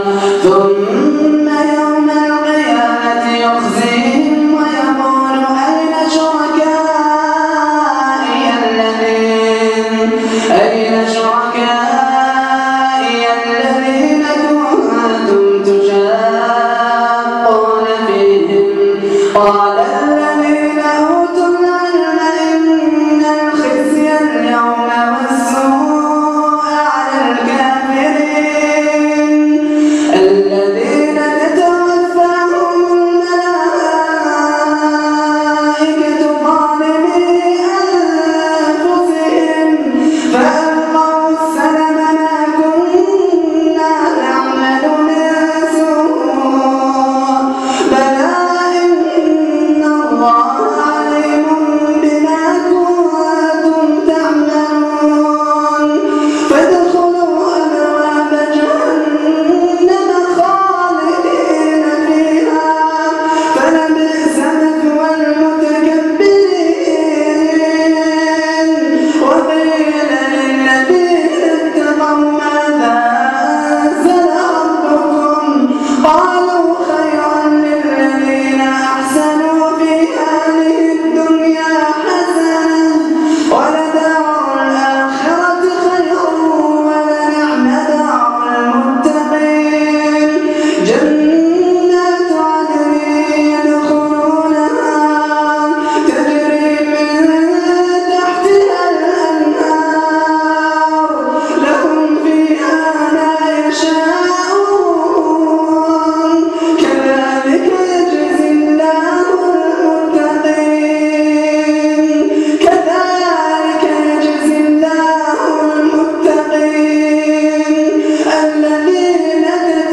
من صلاة التراويح